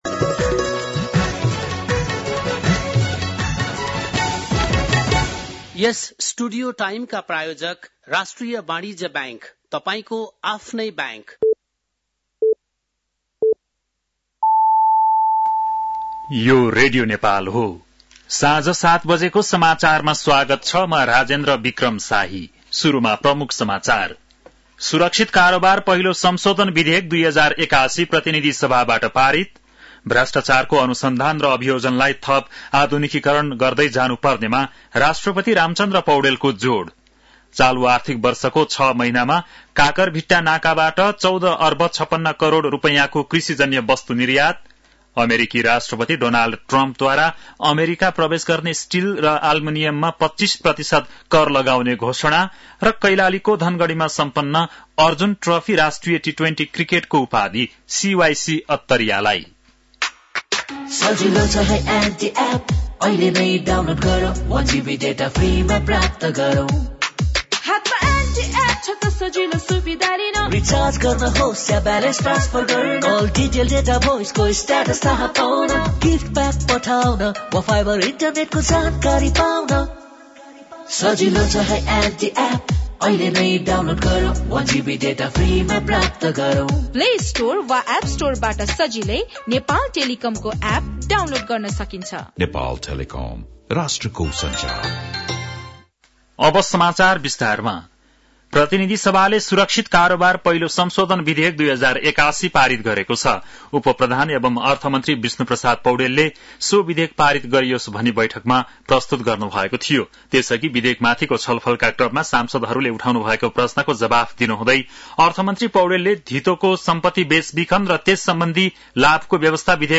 बेलुकी ७ बजेको नेपाली समाचार : २९ माघ , २०८१
7-pm-nepali-news-10-28.mp3